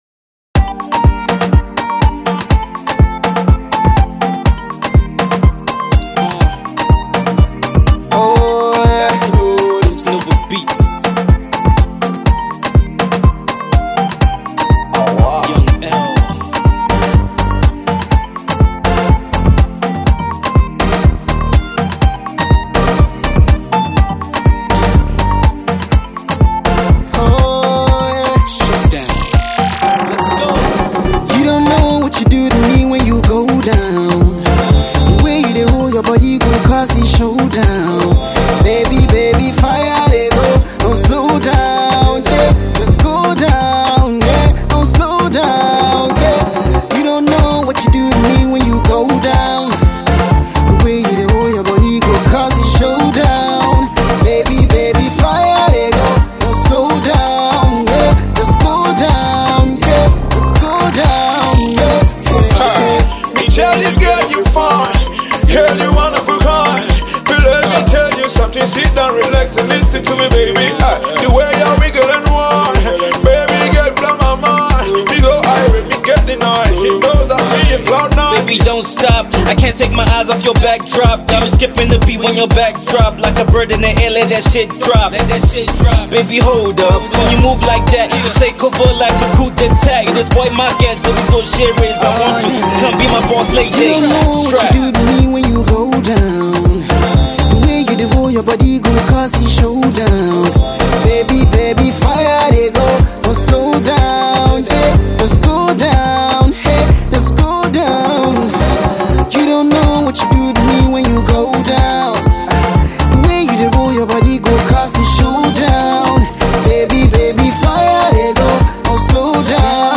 Alternative Pop, Alternative Rap